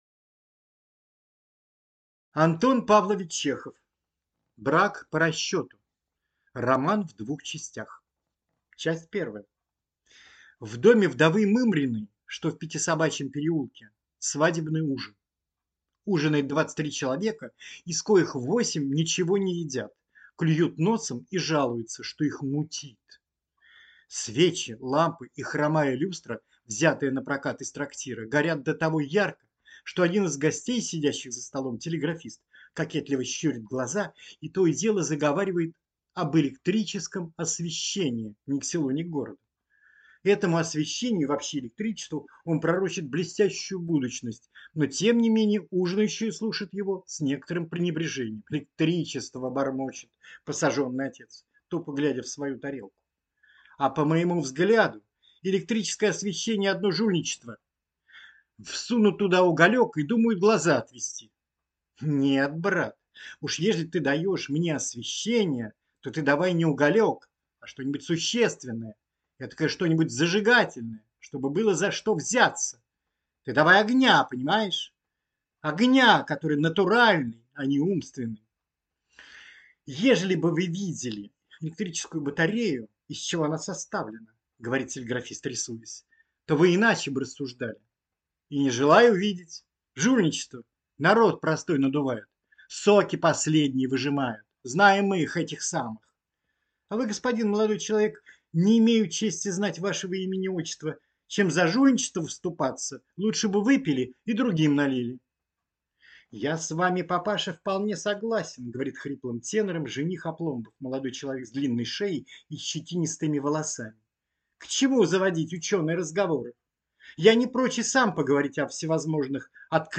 Аудиокнига Брак по расчету | Библиотека аудиокниг